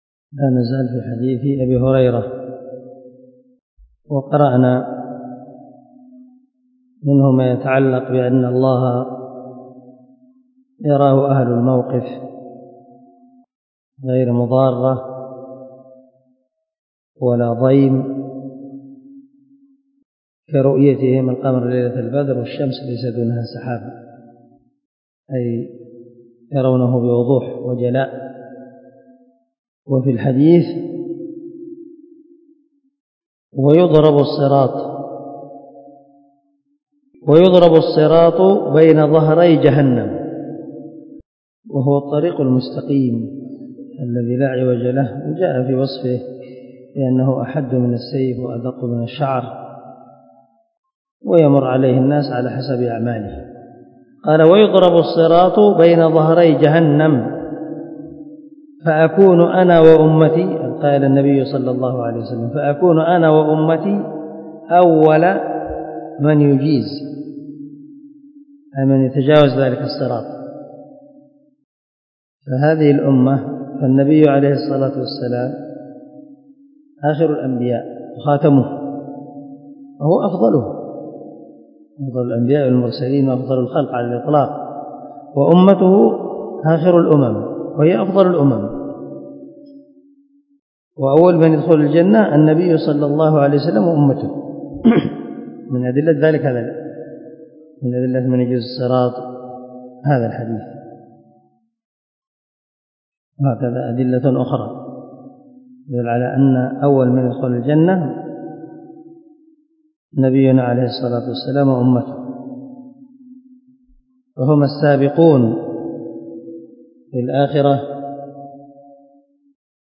سلسلة_الدروس_العلمية